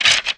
horror
Skeleton Attack Bone Rattle